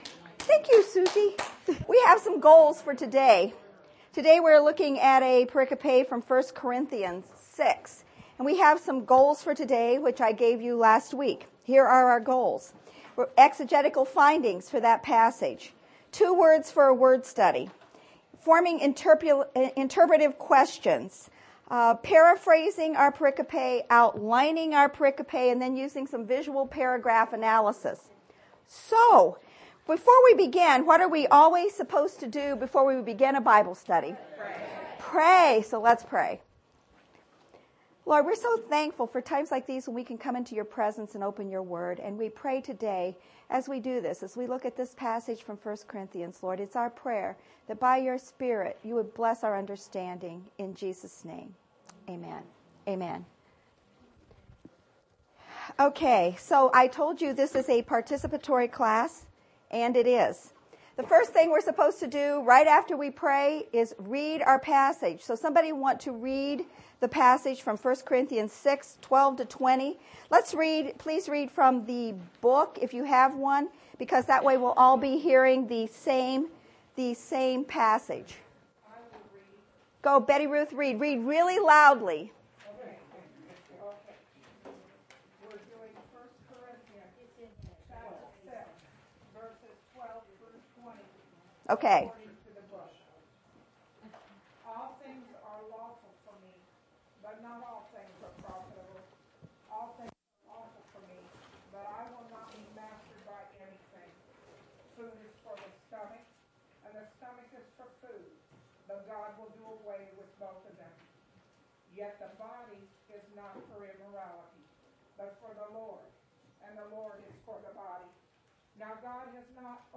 Lesson2-How-to-Study-the-Bible.mp3